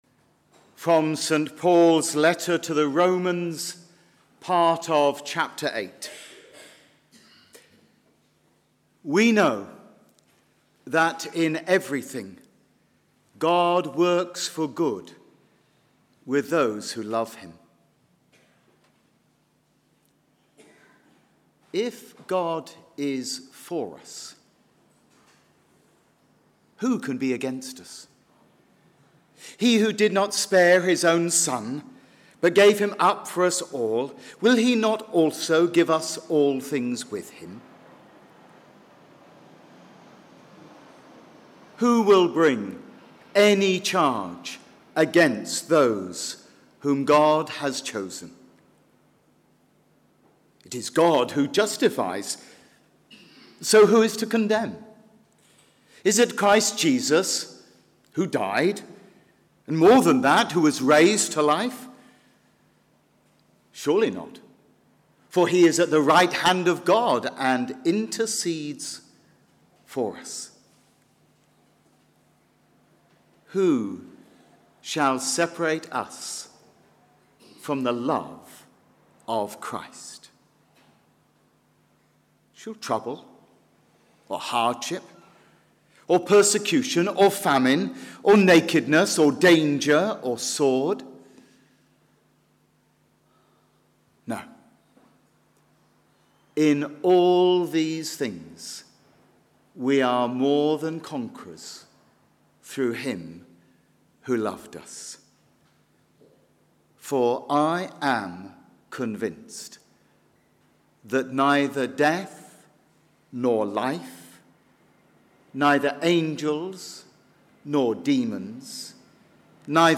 Circuit-Farewell-Sermon.mp3